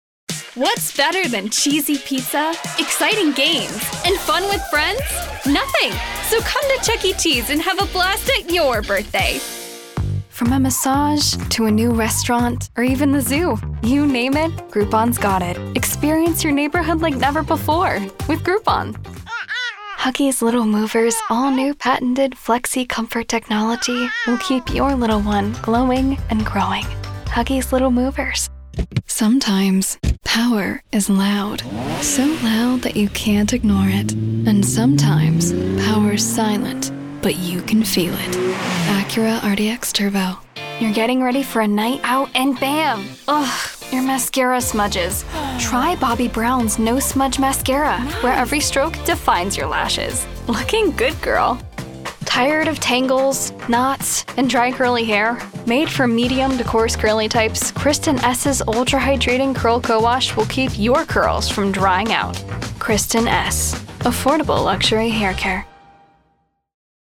Child, Teenager, Young Adult, Adult
Has Own Studio
COMMERCIAL 💸
conversational
upbeat
warm/friendly
husky